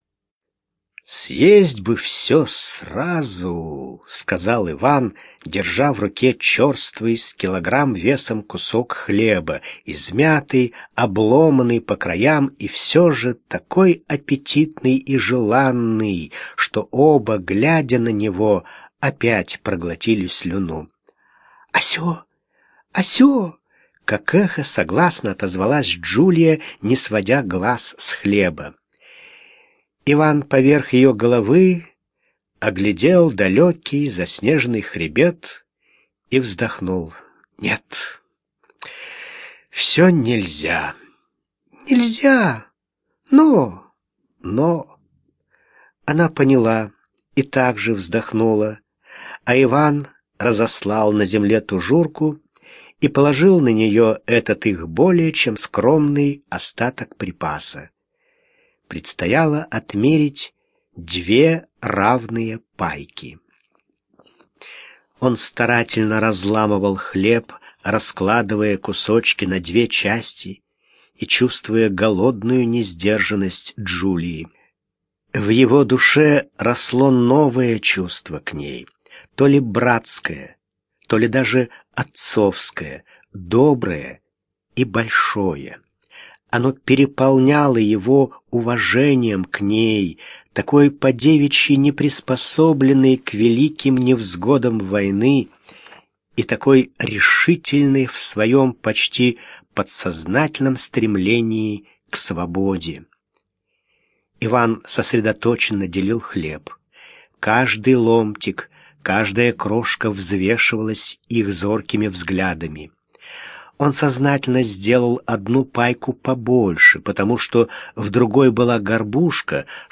Аудиокнига Альпийская баллада
Качество озвучивания весьма высокое.